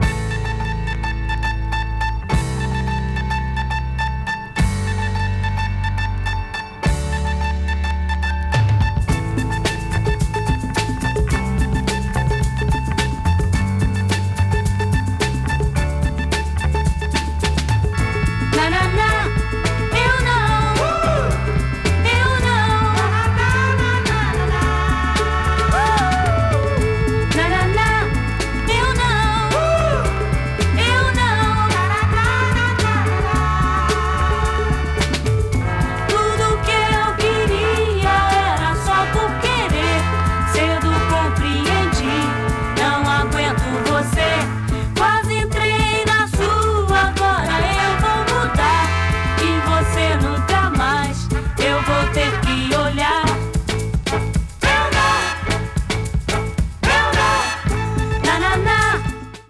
程よくグルーヴィーなナナナ・ブラジリアン・ソフト・ロックです！
70s LATIN / BRASIL 多幸感 詳細を表示する